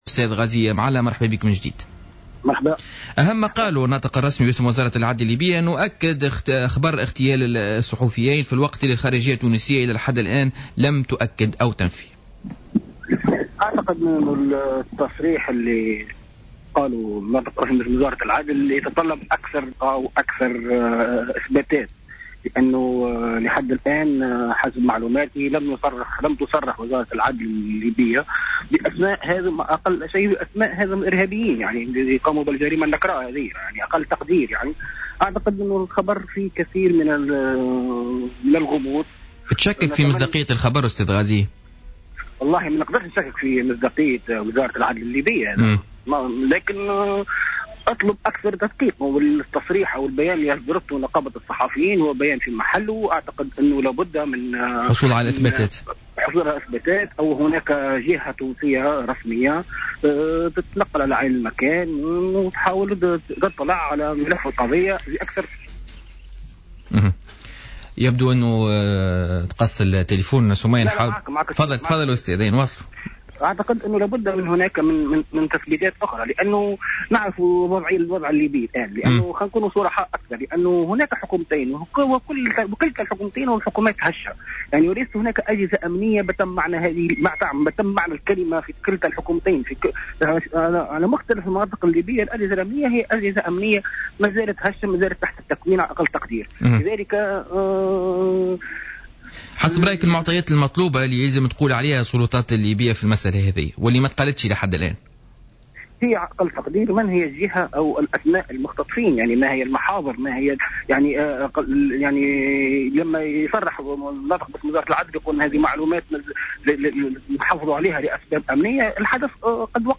في اتصال هاتفي مع الجوهرة أف أم اليوم الخميس من طرابلس